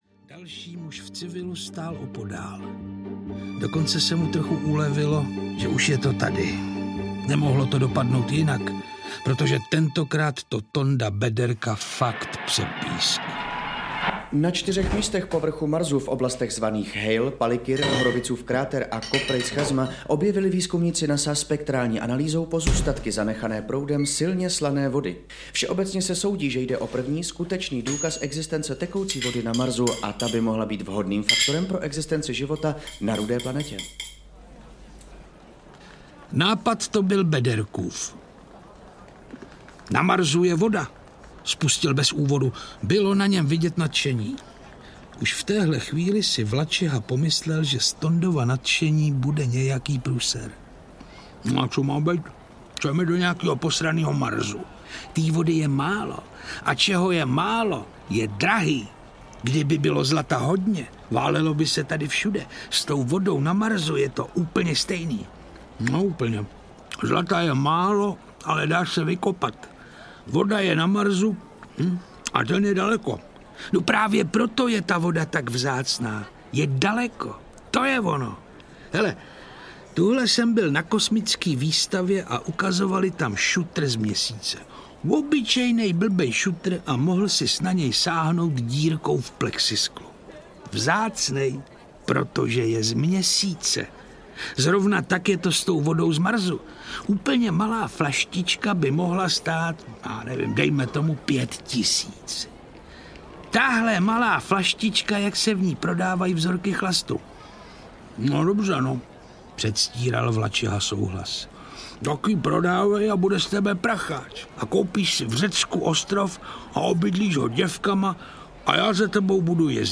Neffova Odysea 2022: Voda na Marsu audiokniha
Ukázka z knihy
• InterpretPetr Čtvrtníček